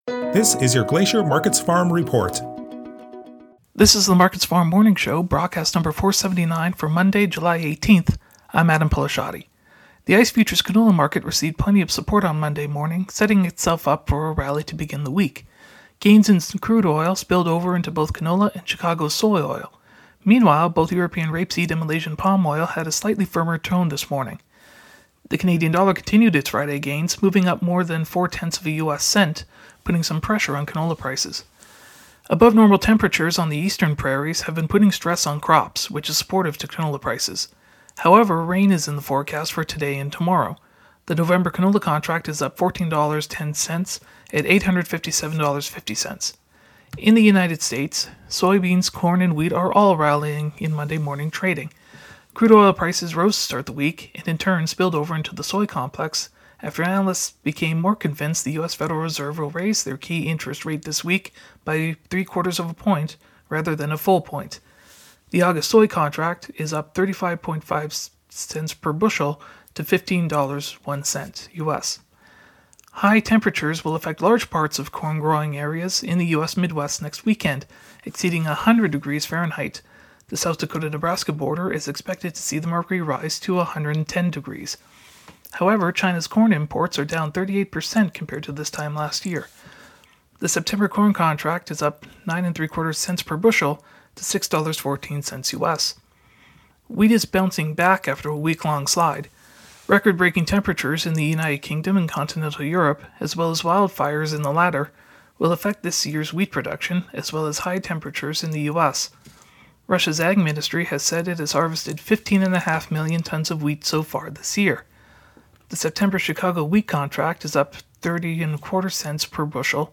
MarketsFarm morning radio show - July 18 - AgCanada